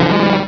Cri de Tadmorv dans Pokémon Rubis et Saphir.